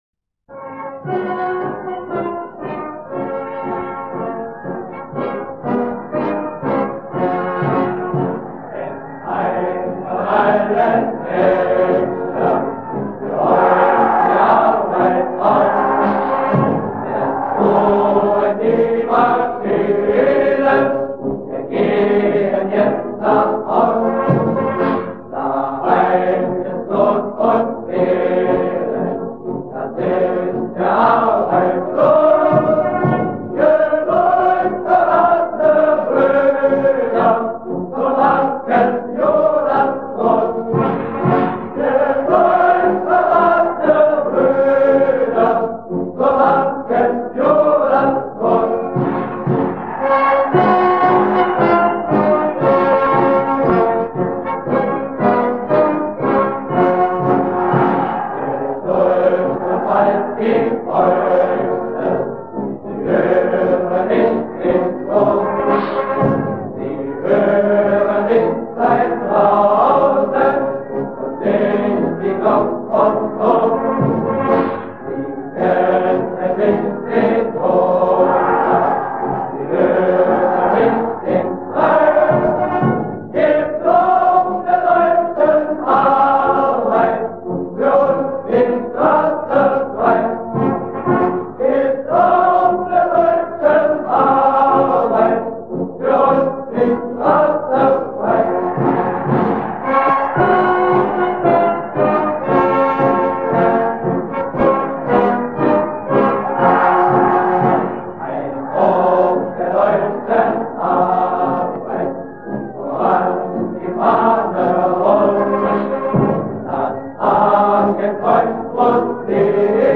WW2 German music